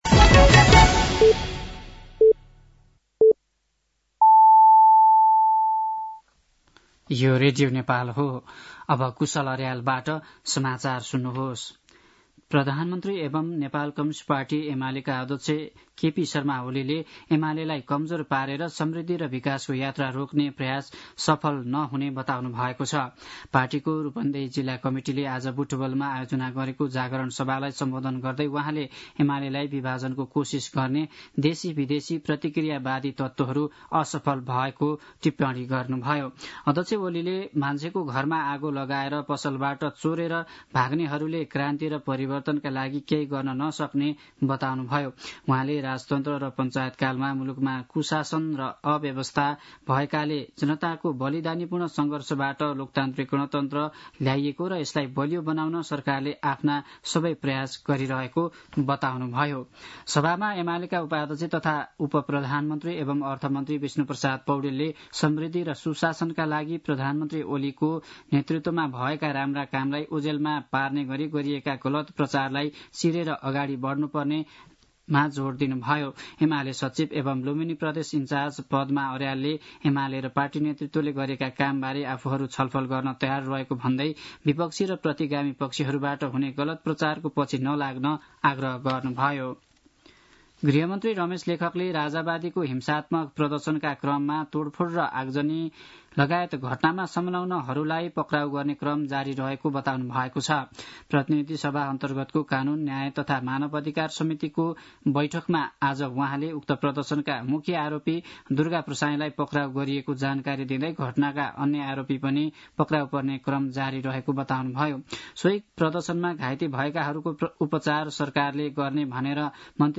साँझ ५ बजेको नेपाली समाचार : २९ चैत , २०८१
5-pm-nepali-news-12-29.mp3